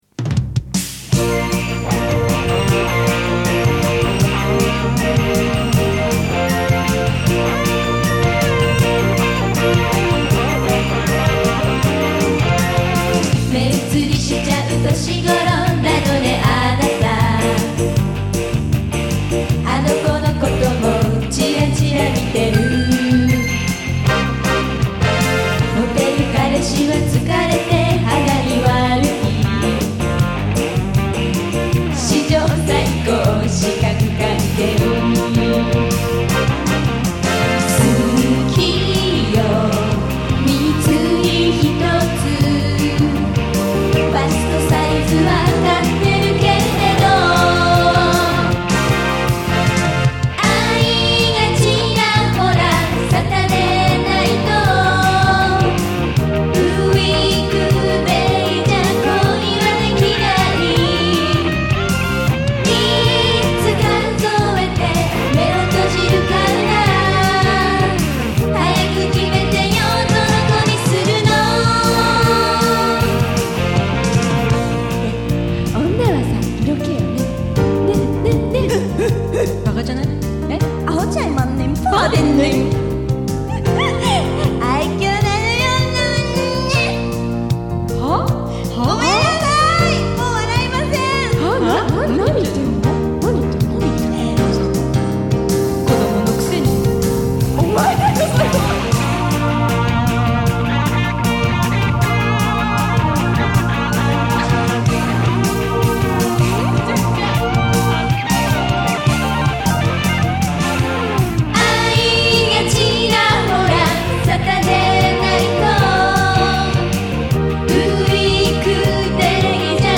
MP3 (mono)